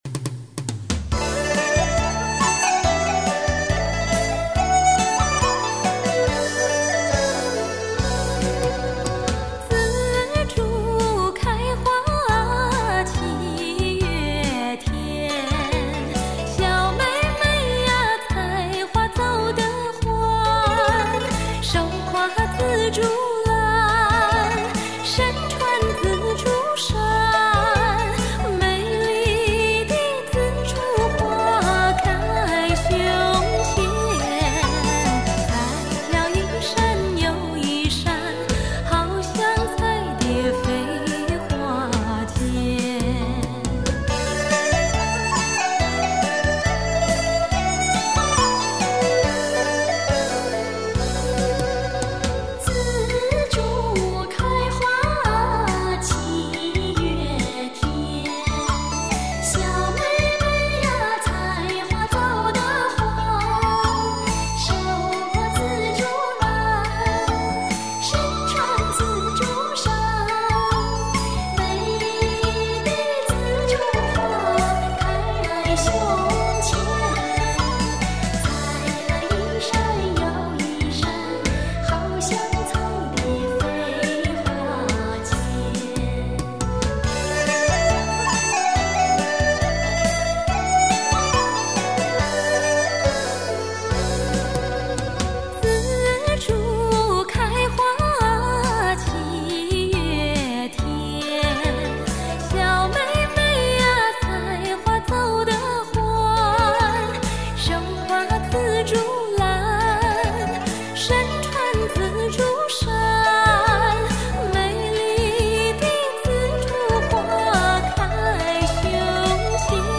中国各地民歌经典，大陆通俗歌星全新演绎